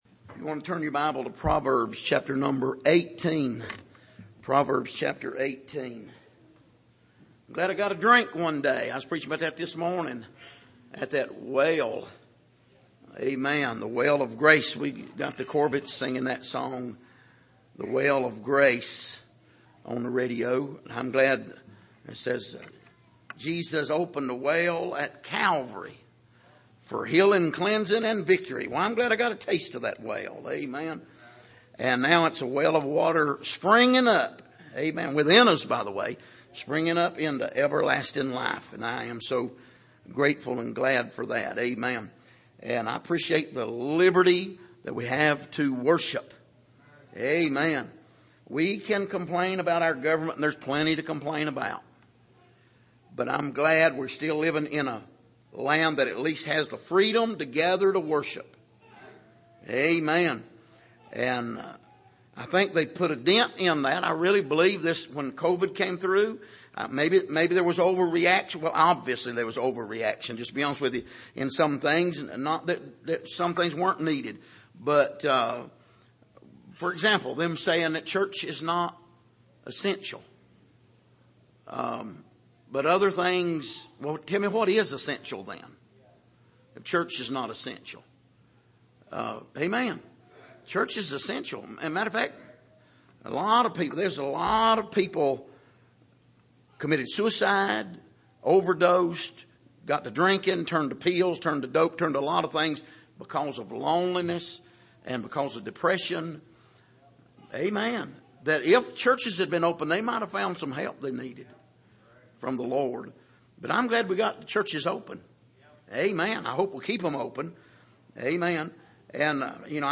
Passage: Proverbs 18:1-8 Service: Sunday Evening